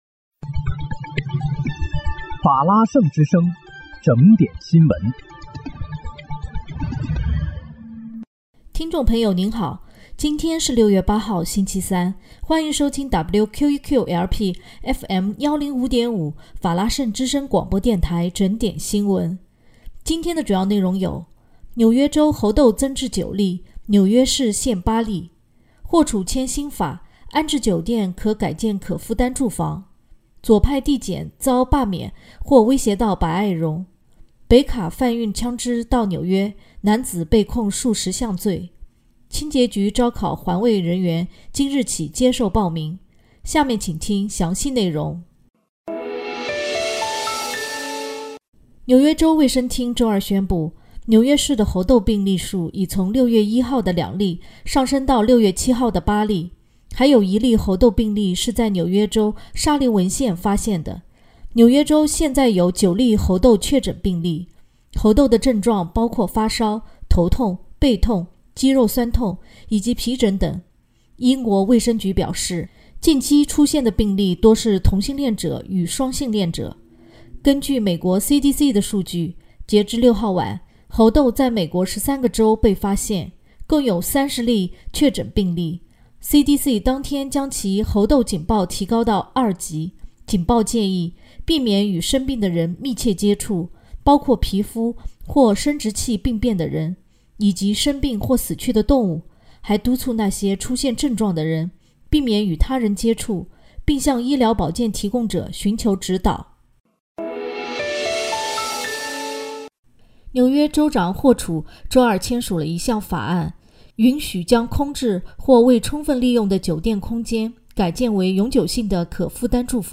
6月8日（星期三）纽约整点新闻